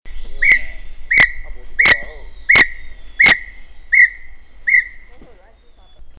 27-5大彎嘴2-2012mar27大凍山.mp3
大彎嘴 Pomatorhinus erythrocnemis
嘉義縣 阿里山 大凍山
錄音環境 闊葉林
行為描述 鳥叫
錄音: 廠牌 Denon Portable IC Recorder 型號 DN-F20R 收音: 廠牌 Sennheiser 型號 ME 67